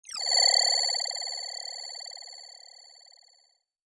Holographic UI Sounds 12.wav